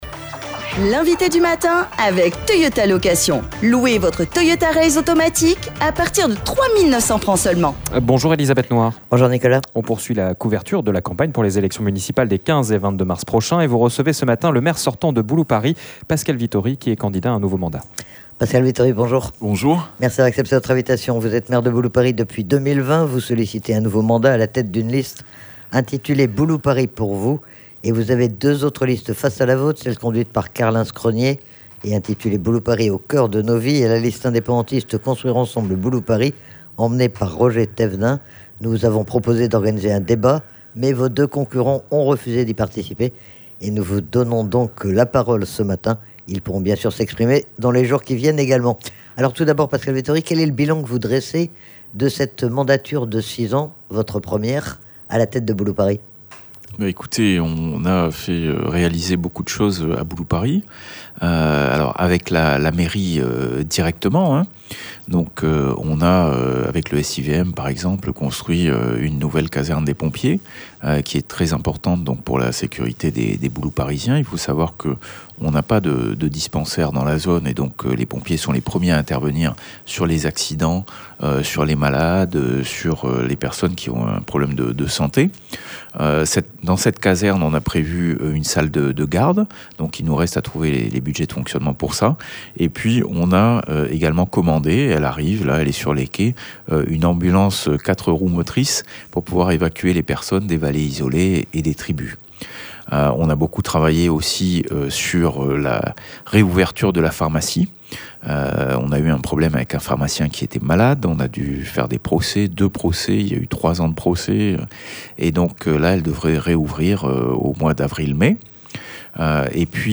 Pascal Vittori était interrogé sur les raisons de sa candidature et sur son programme pour Boulouparis.